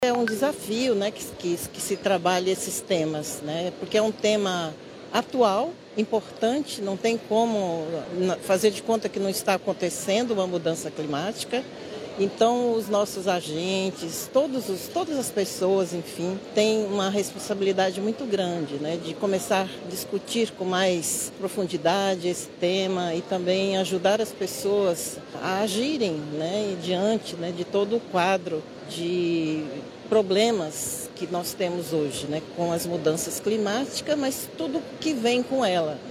O 14º Mutirão Brasileiro de Comunicação (Muticom) aconteceu no Centro de Convenções Vasco Vasques, em Manaus, com o tema “Comunicação e Ecologia Integral: transformação e sustentabilidade justa”.